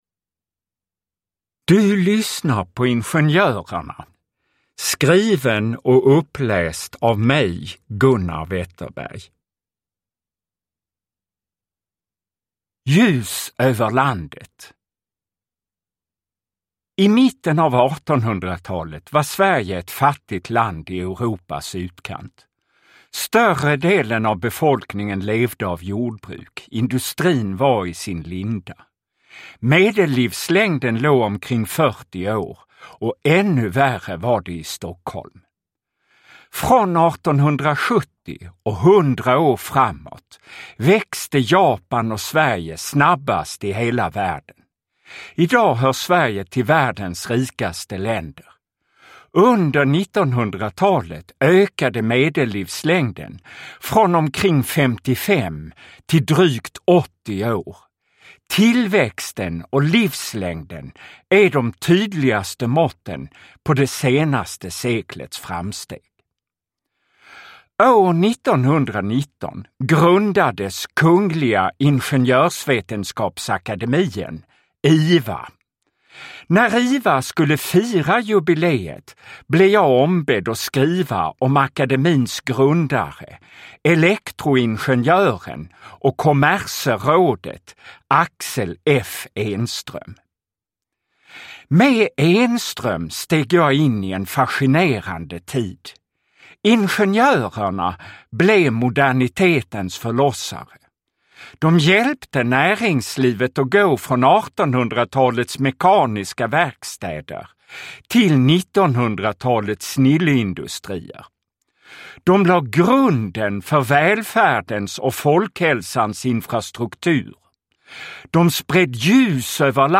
Uppläsare: Gunnar Wetterberg
Ljudbok